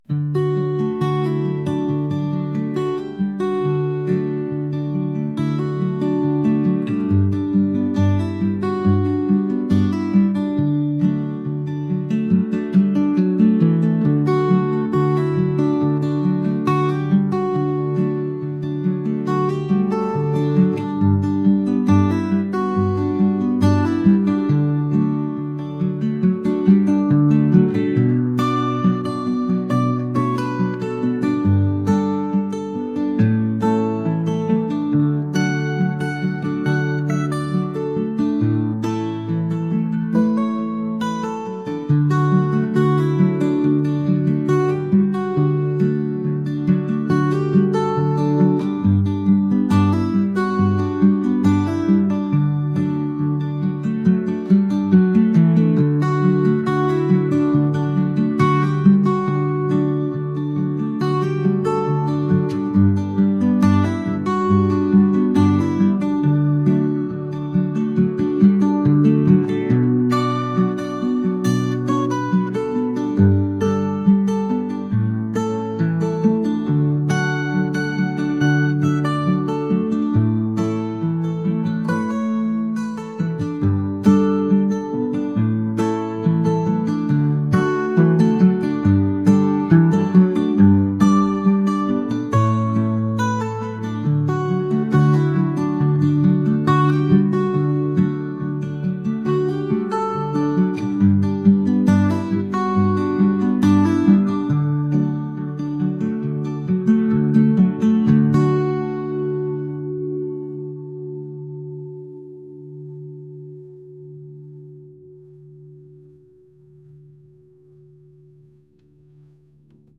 indie | folk | acoustic